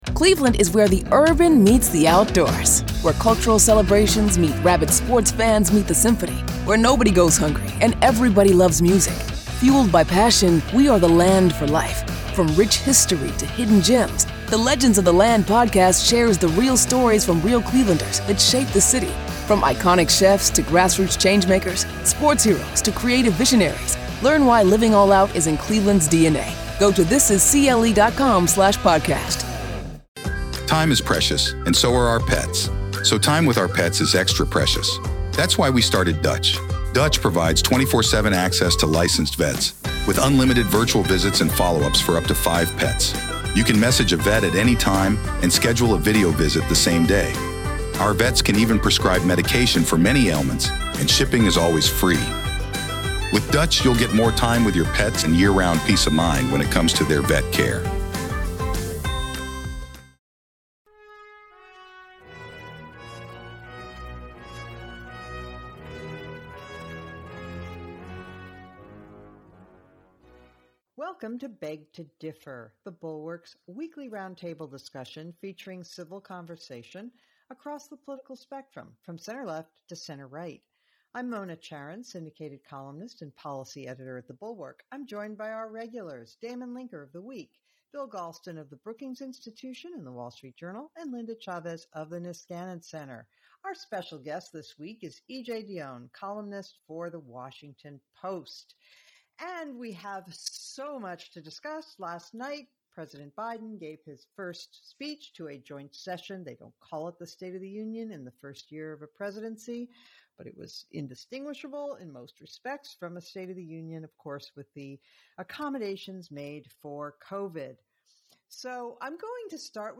E.J. Dionne helps the panel unpack Biden's first 100 days, plus, racial and class messaging.